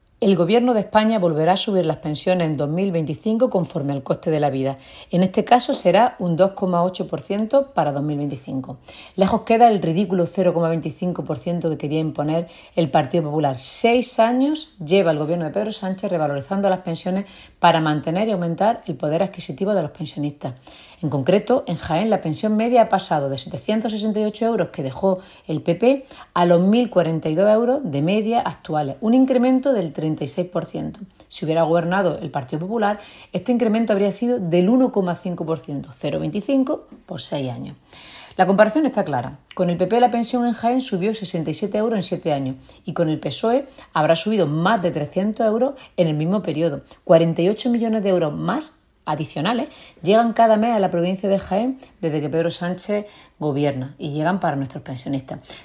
Cortes de sonido
Ana Cobo